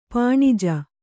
♪ pāṇija